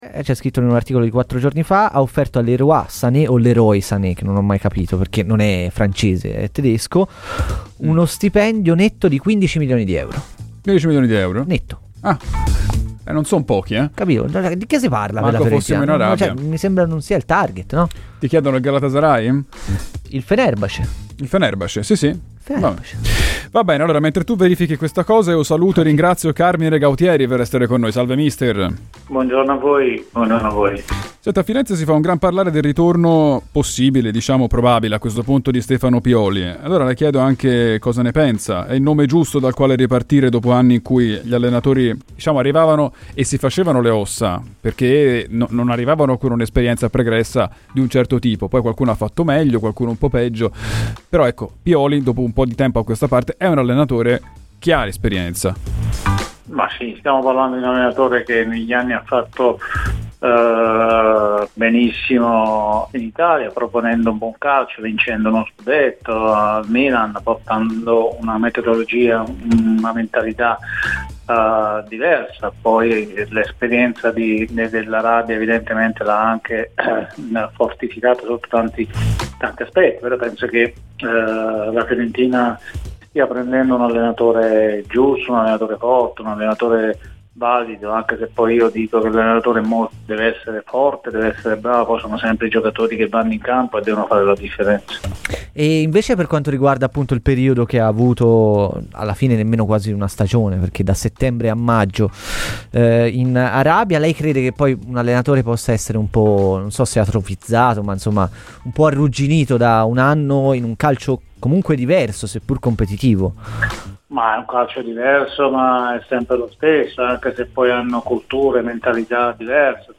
Carmine Gautieri a Radio FirenzeViola